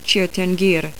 NOW HERE! 12-15-97: Hear how to pronounce 'Tir Tairngire'